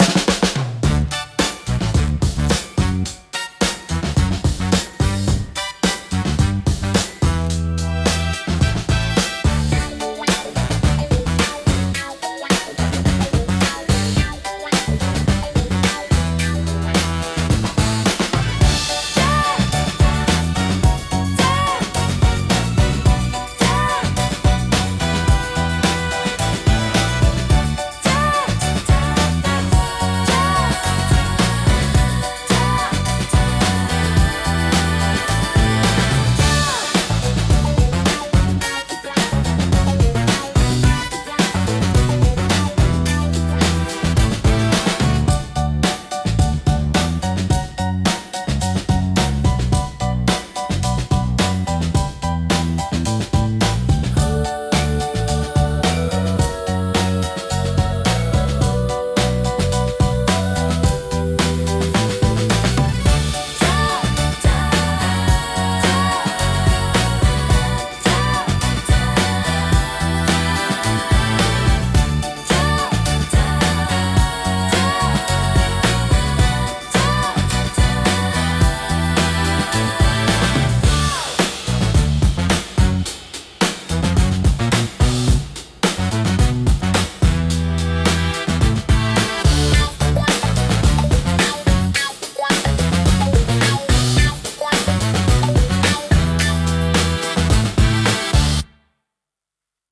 親しみやすいサウンドとポップな雰囲気、明るく感情的な楽曲が多いです。
心に残るハーモニーと温かさが魅力のジャンルです。